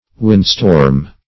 Windstorm \Wind"storm\, n.